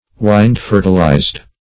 Search Result for " wind-fertilized" : The Collaborative International Dictionary of English v.0.48: Wind-fertilized \Wind"-fer`ti*lized\, a. (Bot.) Anemophilous; fertilized by pollen borne by the wind.